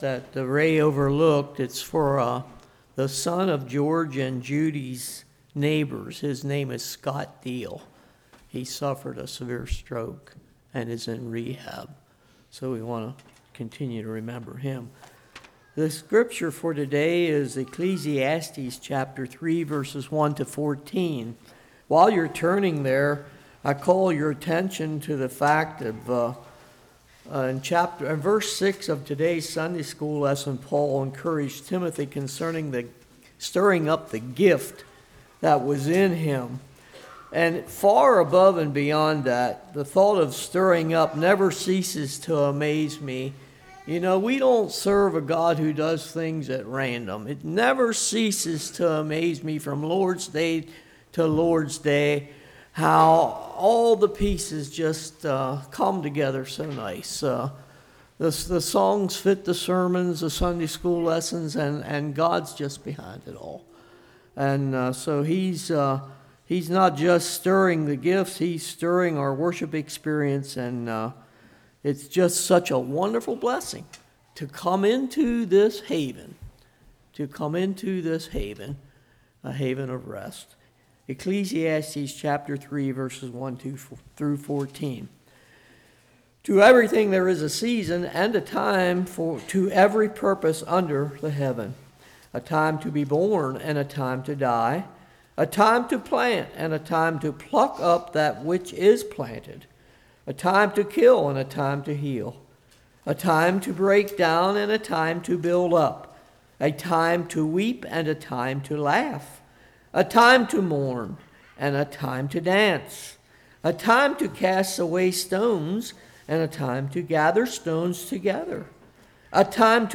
Passage: Ecclesiastes 3:1-14 Service Type: Morning